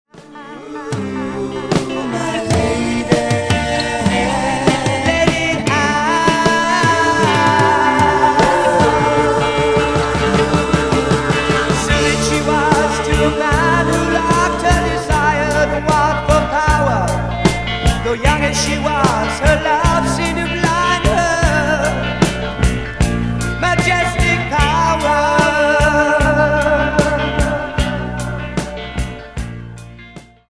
Recorded at Soundstage, Toronto.